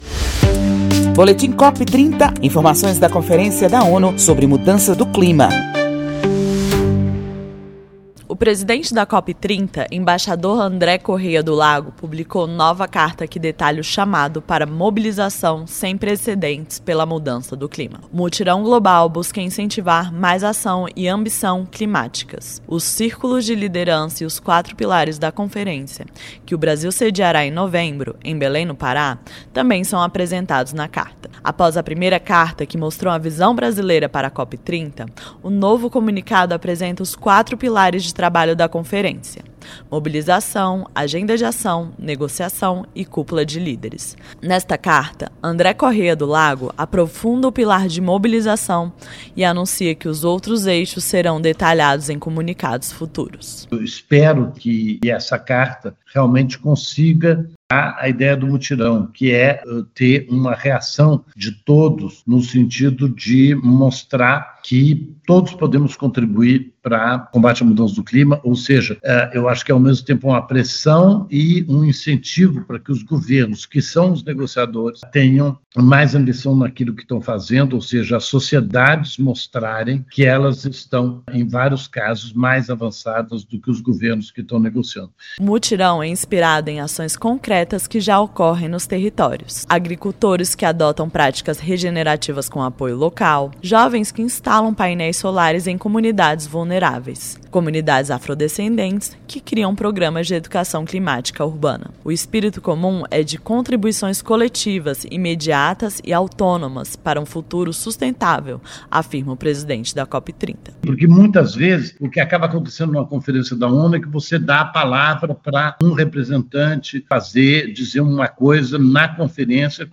Segunda carta da Presidência da COP30 apresenta proposta para mobilização e pede novos modelos de governança para lidar com crise climática e complexidade do século 21. Ouça a reportagem e saiba mais.